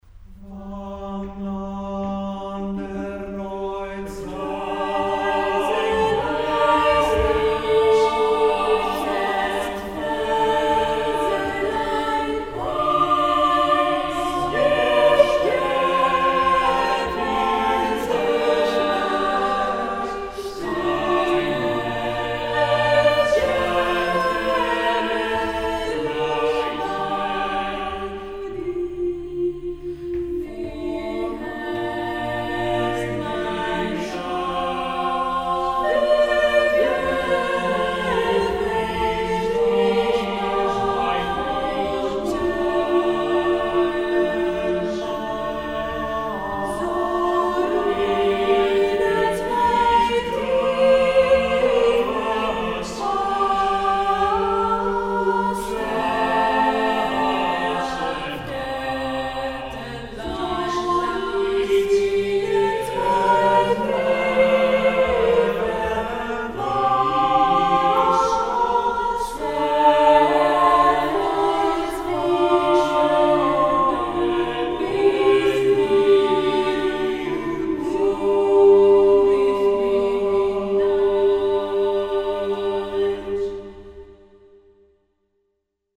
Ηχογράφηση από την πρόβα της Τρίτης 2 Μαΐου 2006.
Με ισοστάθμιση, αντήχηση και στερεοφωνία
eq = Equalizer, rev = reverb